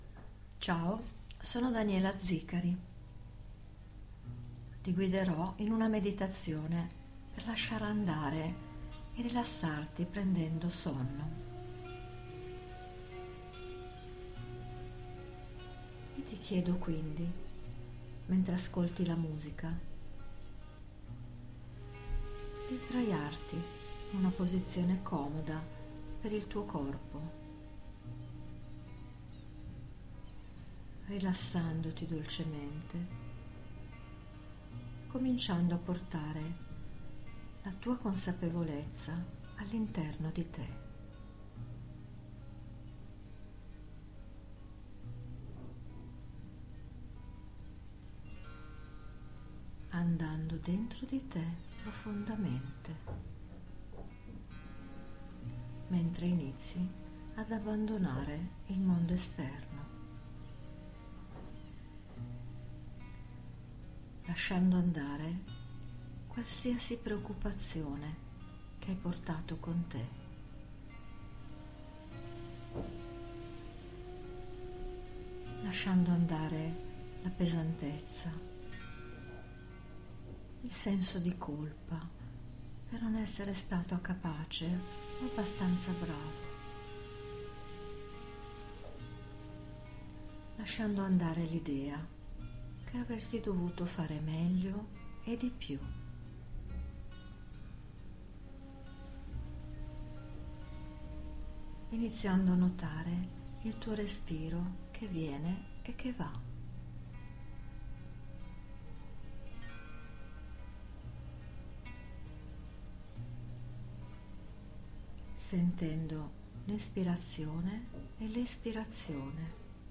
Meditazione guidata sul RILASSAMENTO - Scarica o ascolta Gratis MP3